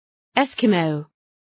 {‘eskə,məʋ}